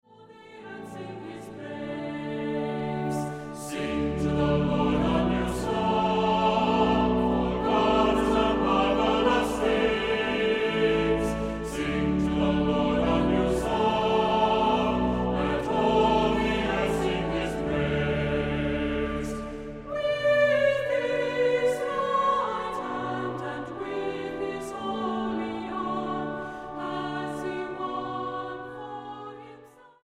Voicing: Unison with descant; Cantor; Assembly